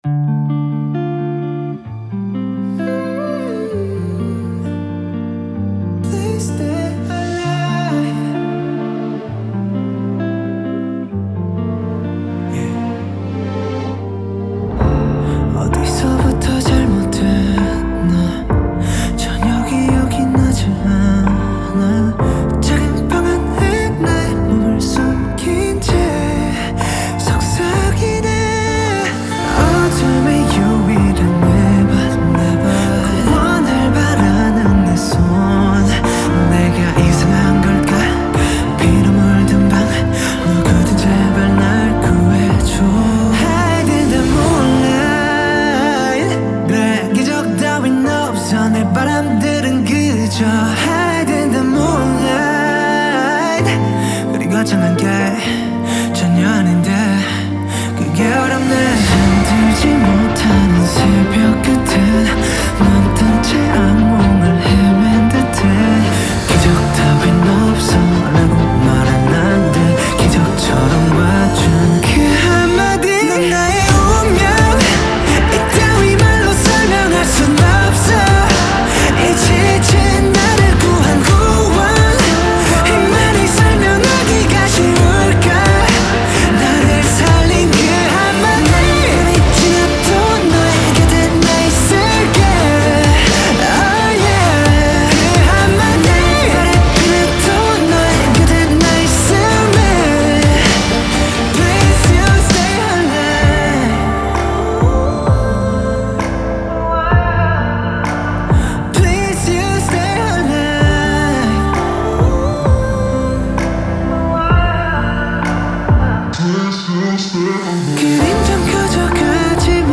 The new single project has a lovely tune to it.